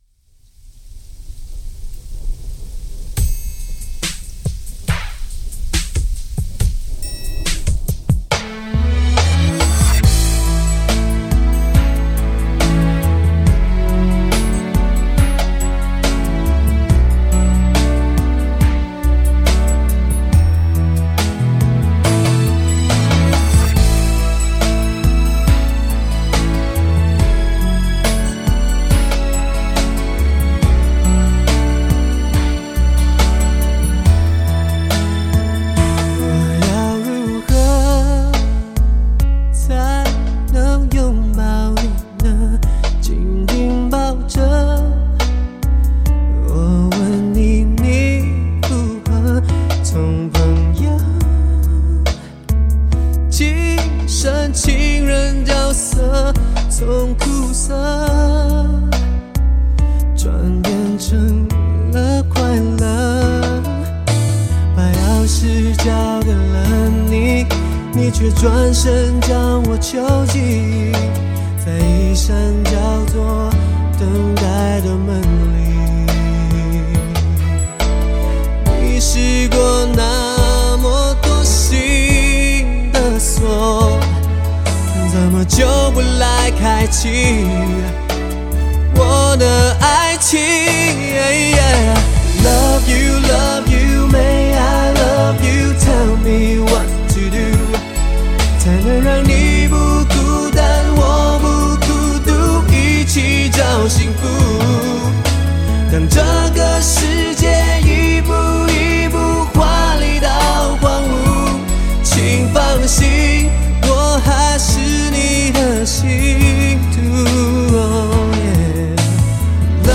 浓绿 纯净 好男声的好男生